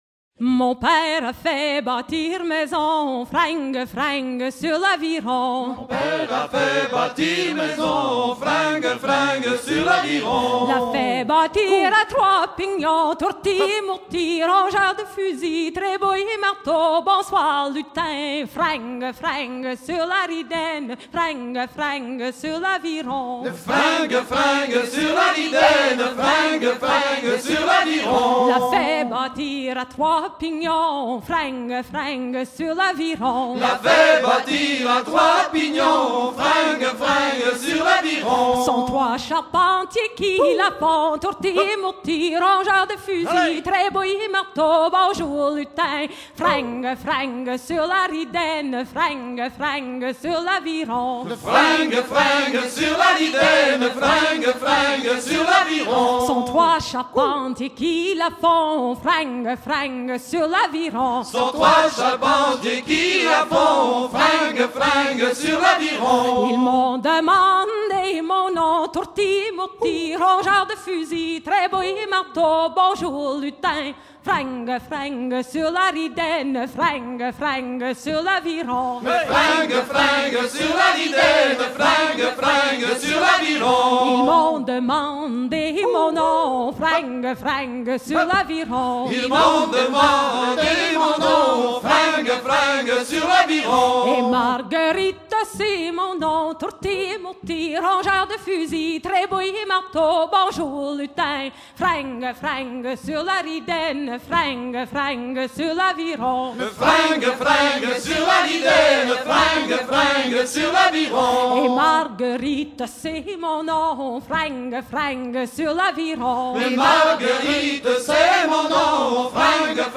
Note enregistrement lors du concert de chants de marins en 1999 La grande rencontre à Montréal
Fonction d'après l'analyste gestuel : à ramer ;
Genre laisse